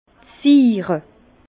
Bas Rhin d' Siire
Prononciation 67 Herrlisheim